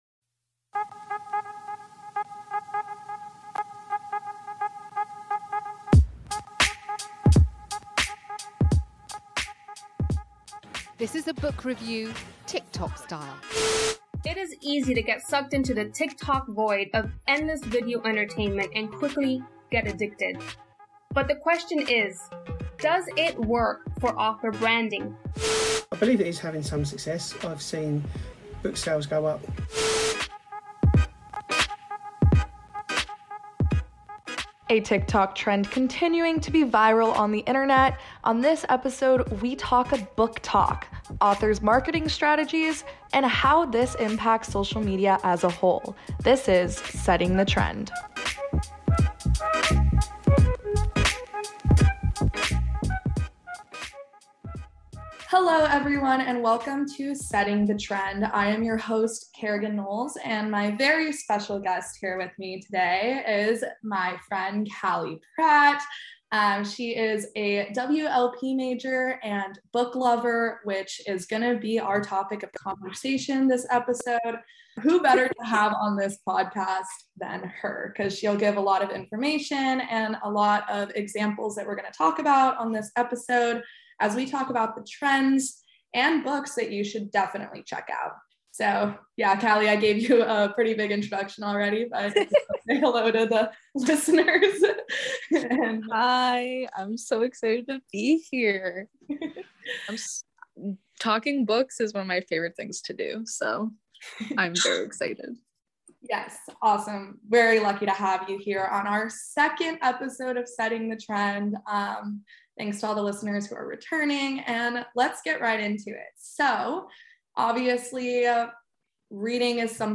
0:00 - Music intro 0:10 - Media introduction clips from news organizations about what is trending